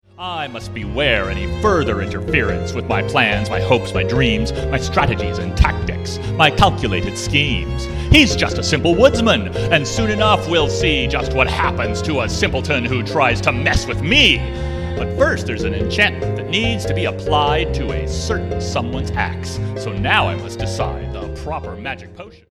The primary vibe is the golden age of Broadway, but there’s some gospel, there’s jazz, there’s funk; there’s even a bit of boy-band/BTS snuck in here and there. Here are some rough demos of a few of the songs:
Tracks below this line are short excerpts of the songs.